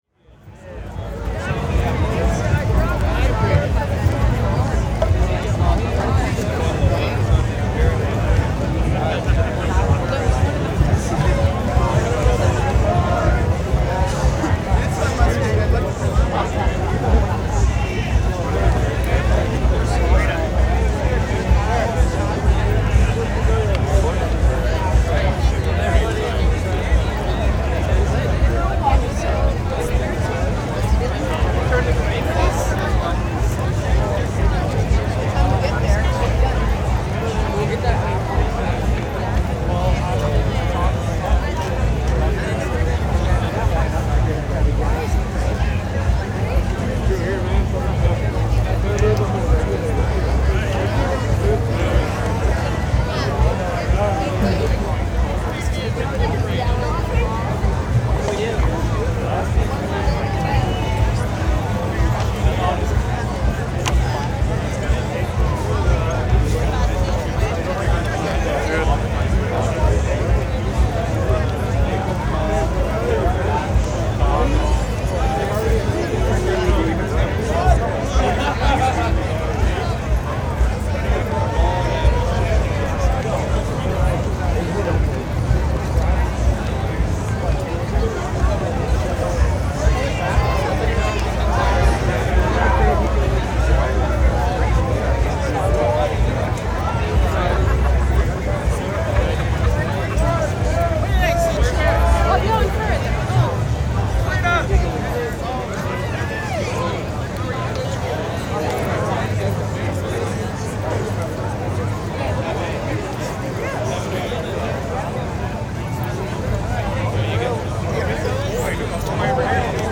streetparty3.L.wav